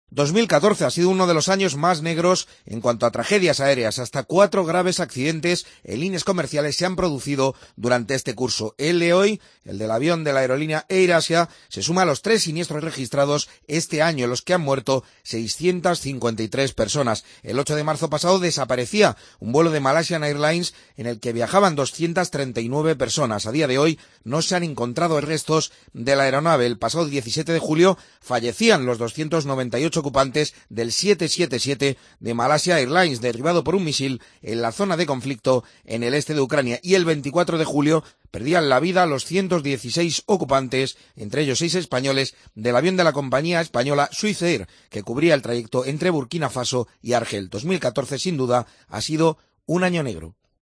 Entrevistas en Mediodía COPE